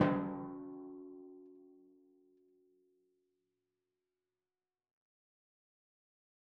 Timpani5_Hit_v4_rr2_Sum.wav